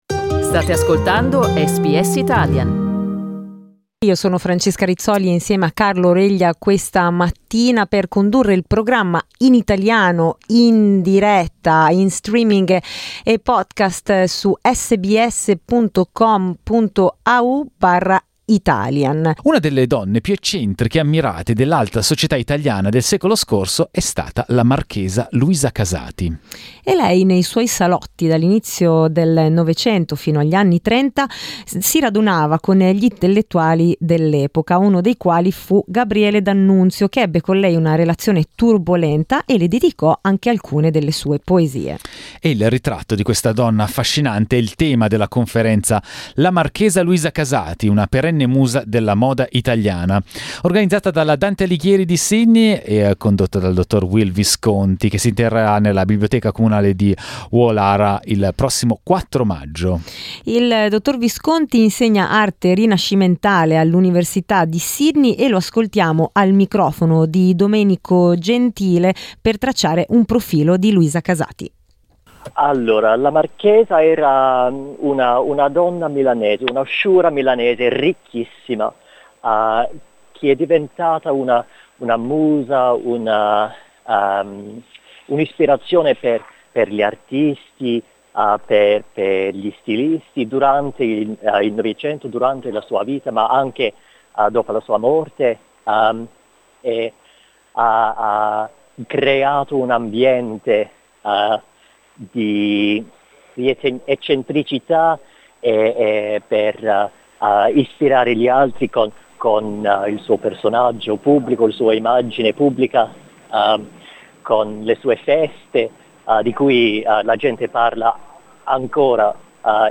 Ascolta l'intervista: LISTEN TO La Marchesa Casati, storia di una nobildonna icona della moda SBS Italian 10:20 Italian Le persone in Australia devono stare ad almeno 1,5 metri di distanza dagli altri.